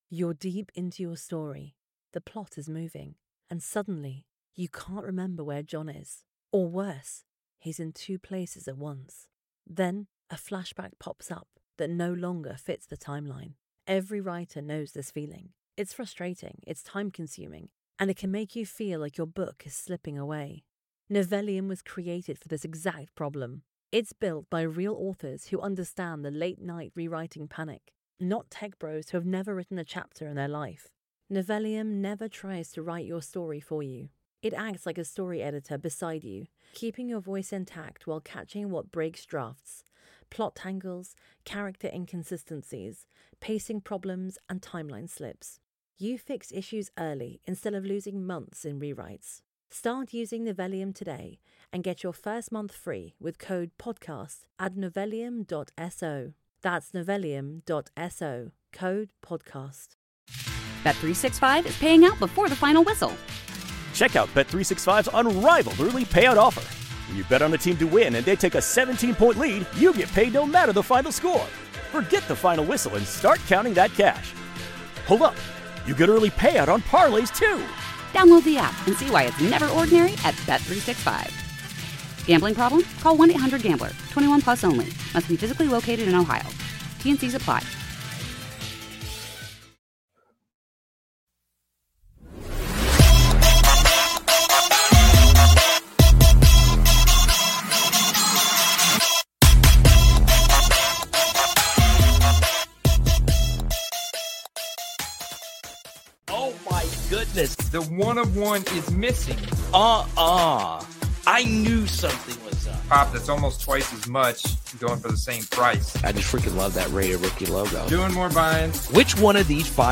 NFL Week 12: Buy Low, Sell HIGH! 2025 Football Card Flipping Secrets Revealed LIVE